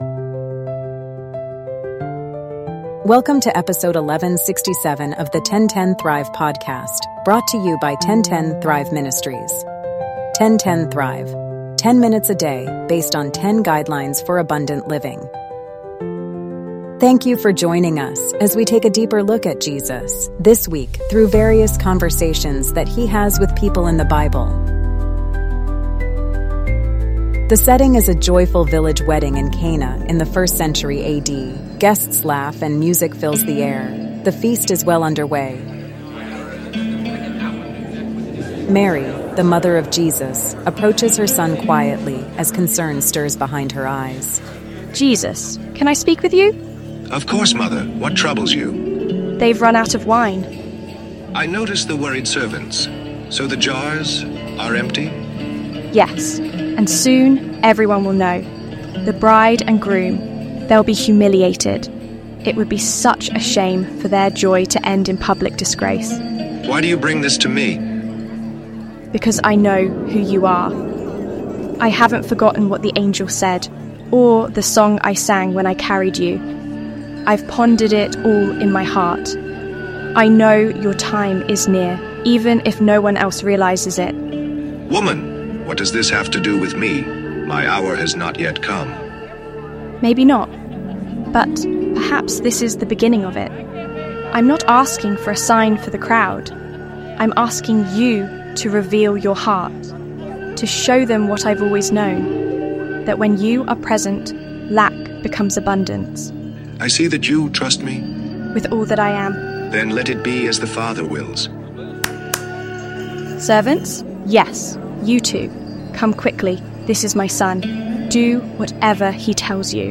The episode begins with a dramatized conversation between Jesus and His mother, Mary, revealing both the human concern of running out of wine and the divine readiness for something greater.